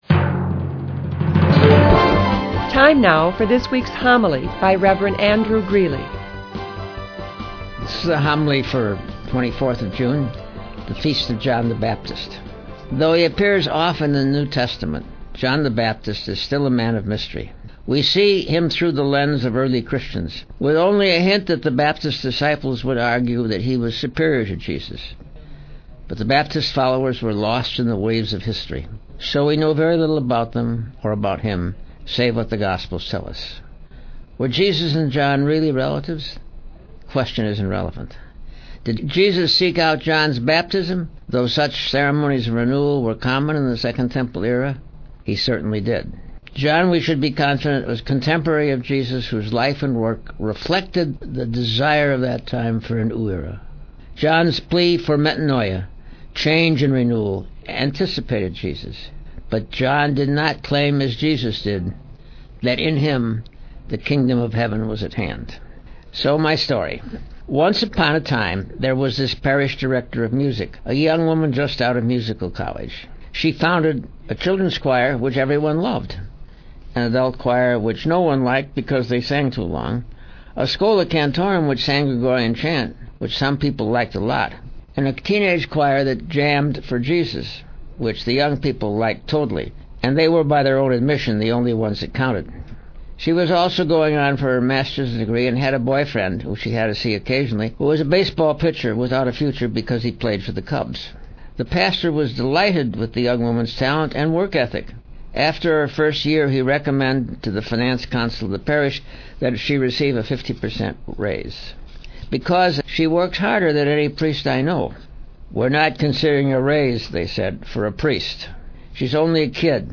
Father Greeley has started doing his Homilies on Revelant Radio WCSN 820 AM.
The Latest Audio Homilies from the Archdiocese of Chicago (mp3)
Homily 06.24.2007: Aired: 06/24/2007;